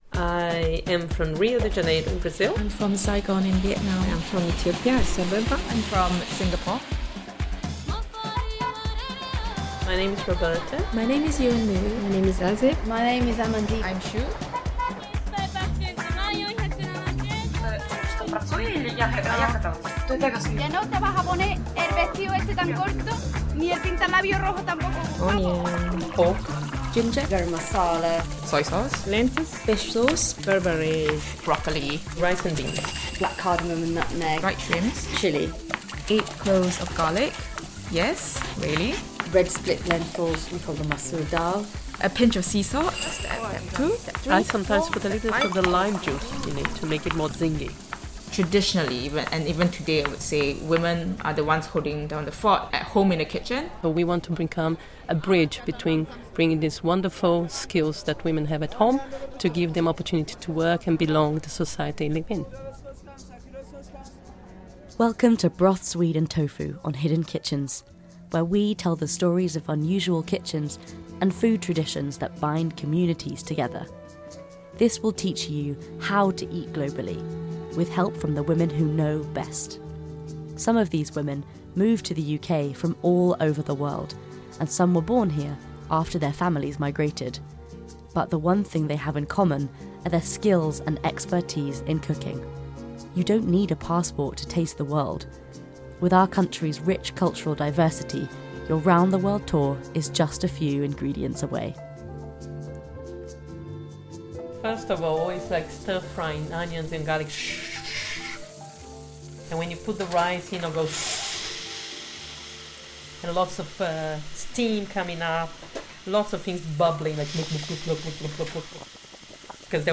Broth, Swede and Tofu Radio Documentary
Charles Parker Prize Shortlisted feature: